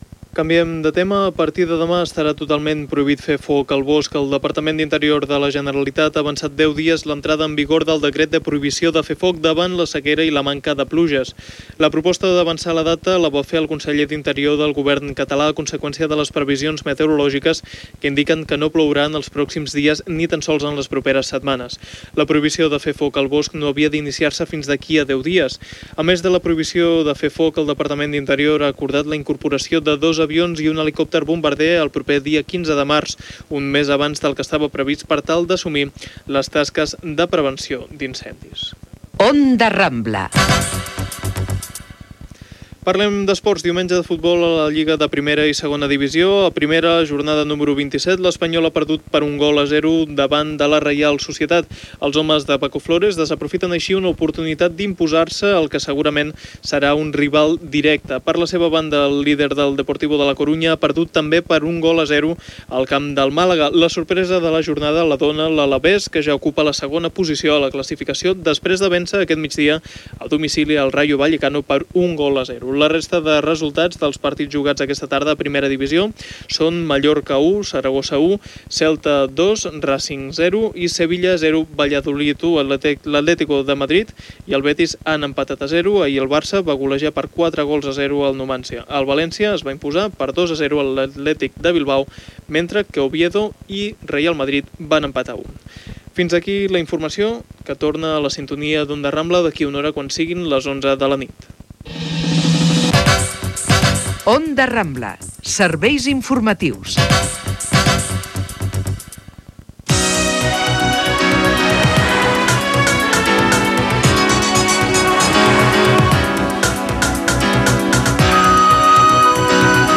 Prohibició de fer foc al bosc, resultats de la lliga de futbol, careta de sortida i indicatiu de l'emissora.
Informatiu
FM